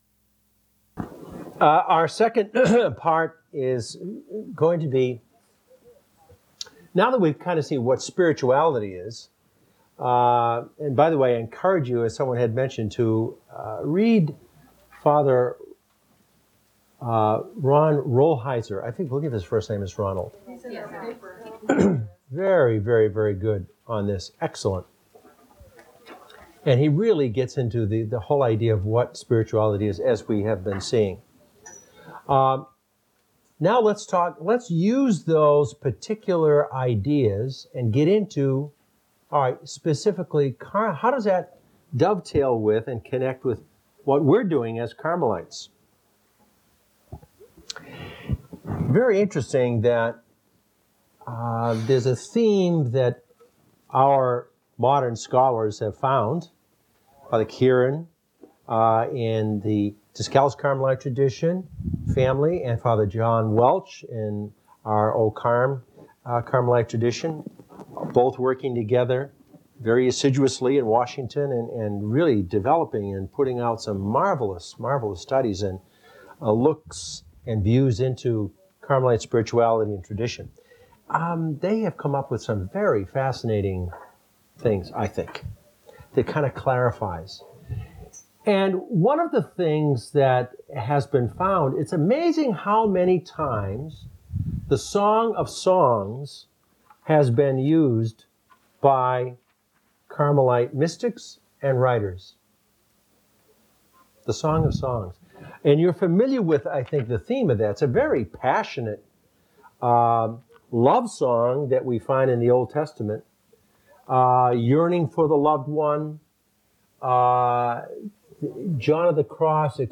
Posts about Religious Talks written by Carmelite Institute of Spirituality
(continued from the morning talk) Afternoon talk recorded on Unity Day, May 8, 2004